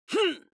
دانلود آهنگ دعوا 21 از افکت صوتی انسان و موجودات زنده
دانلود صدای دعوا 21 از ساعد نیوز با لینک مستقیم و کیفیت بالا
جلوه های صوتی